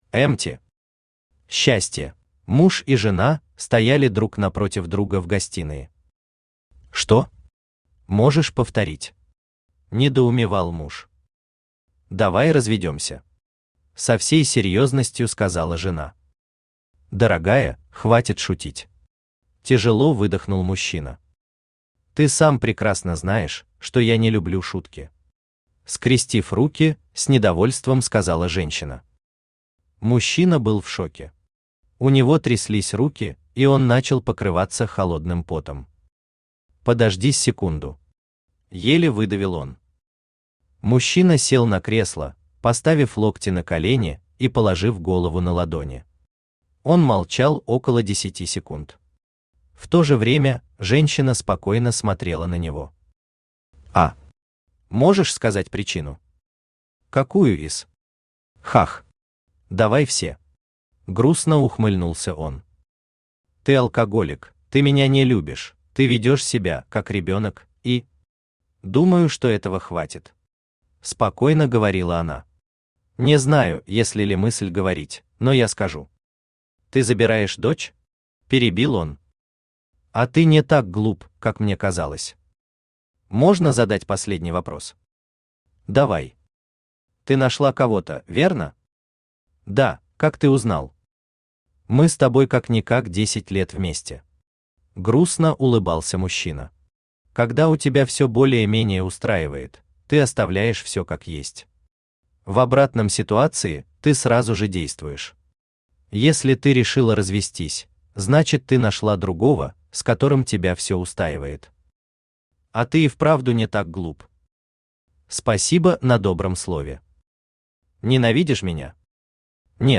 Aудиокнига Счастье Автор Emty Читает аудиокнигу Авточтец ЛитРес.